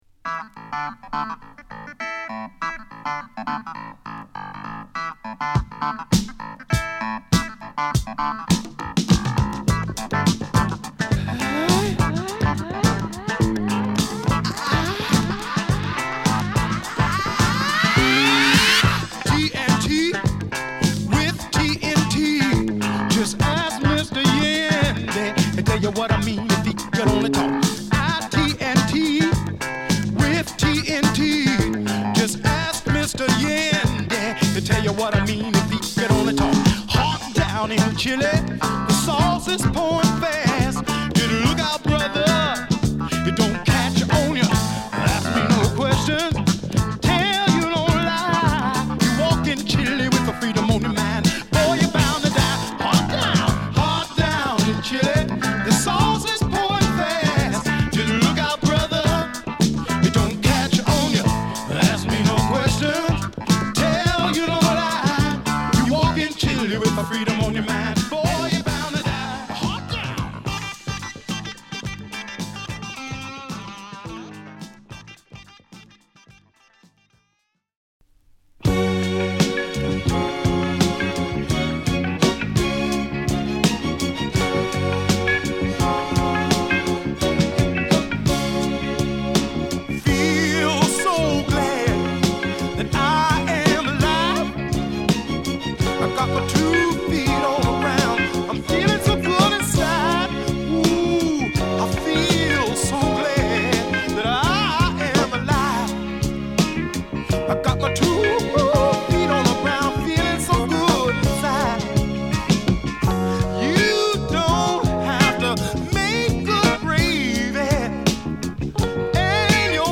キラーなイントロ！
随所にドラムブレイクあり！！！